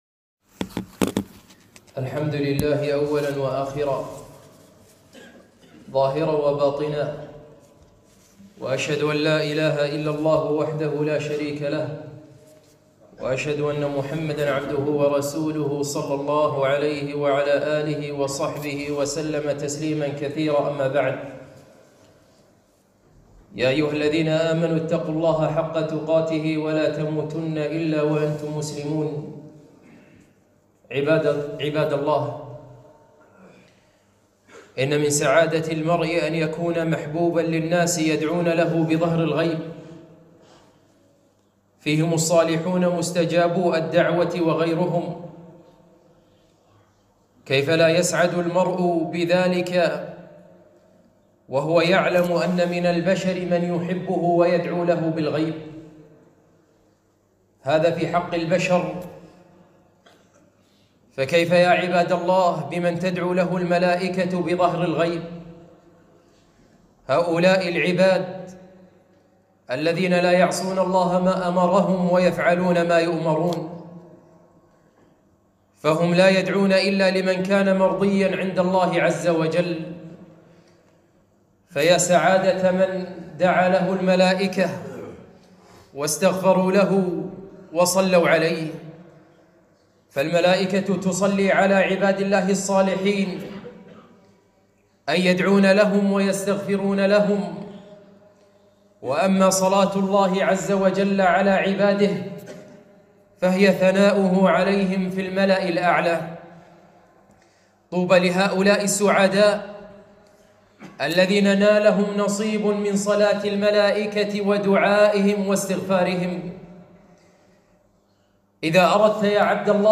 خطبة - ذا أردت أن تدعو لك الملائكة